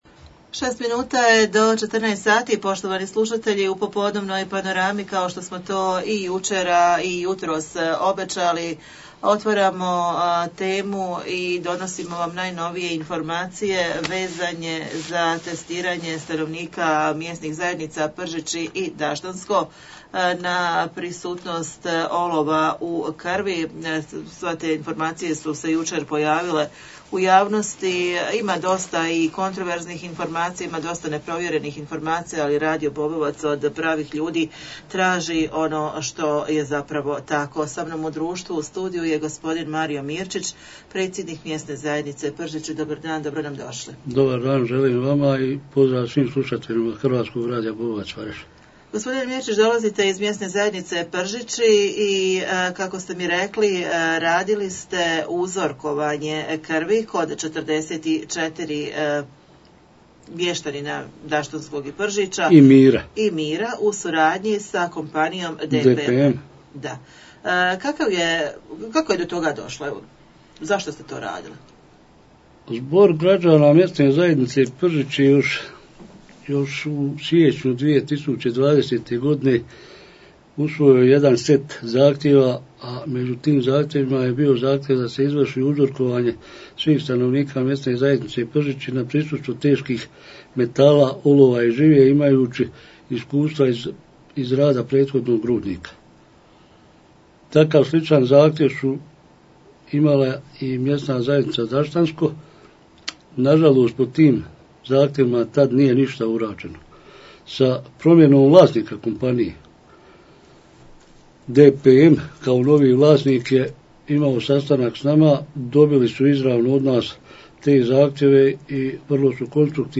Razgovarali smo s predsjednikom MZ Pržići Mariom Mirčićem o osobama koje su testirane na prisustvo olova i povećanim koncetracijama, poslušajte ....